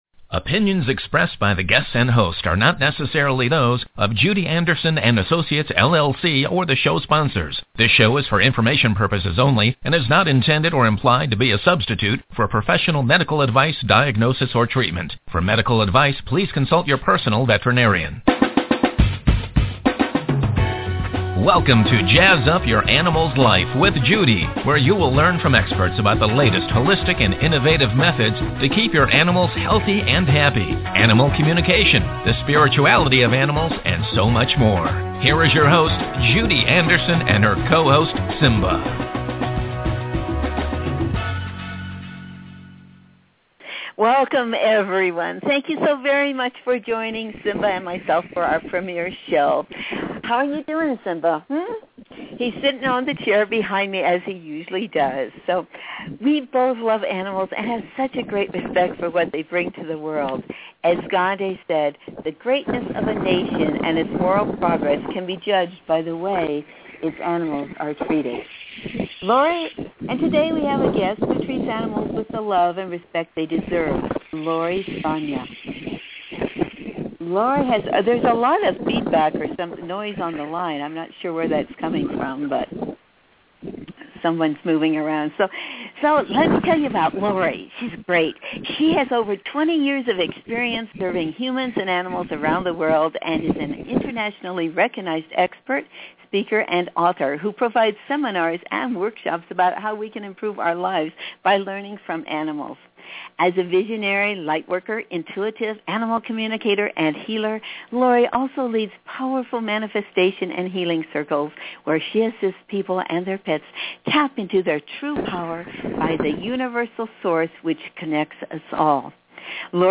So enjoy this fascinating conversation